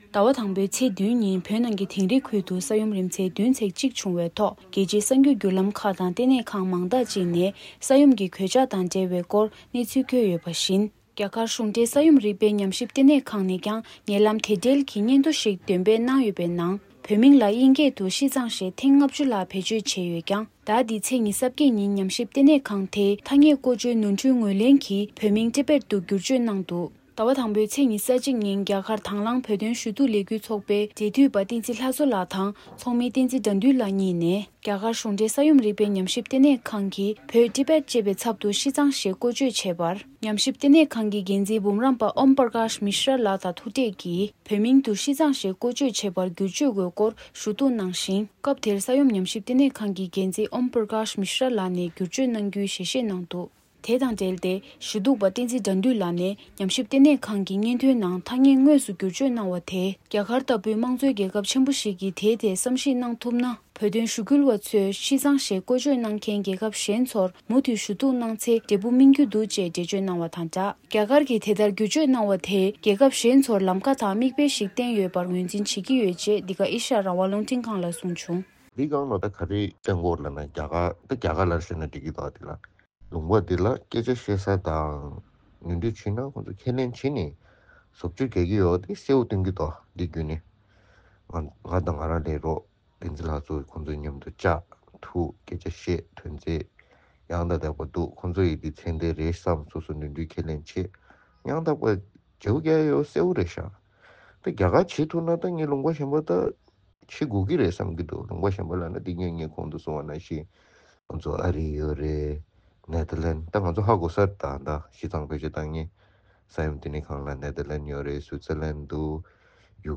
སྒྲ་ལྡན་གསར་འགྱུར། སྒྲ་ཕབ་ལེན།
གསར་འགོད་པ།